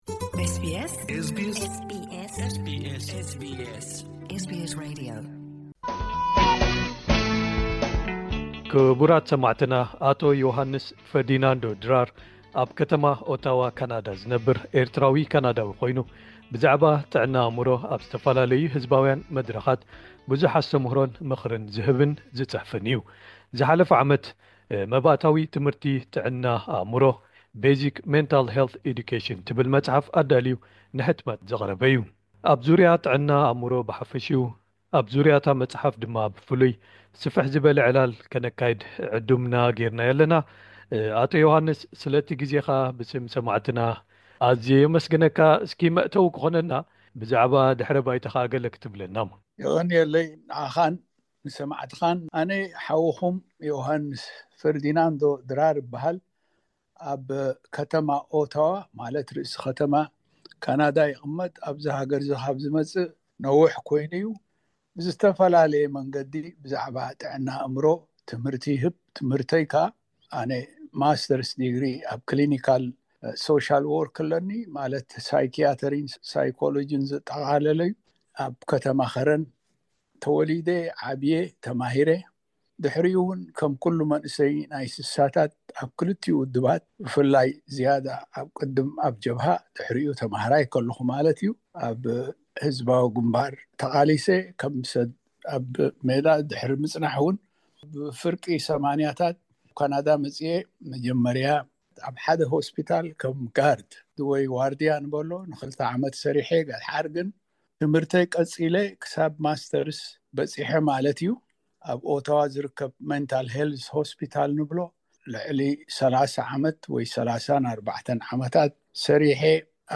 ኣብ ዙርያ ጥዕና ኣእምሮ ብሓፈሽኡን ልሙዳት ተርእዮታት ጸገማቱ ብፍላይ ከምኡ'ውን ኣብ ዙርያ'ታ ዝጸሓፋ መጽሓፍ ዕላል ከነካይድ ዕዱምና ገይርናዮ ኣለና።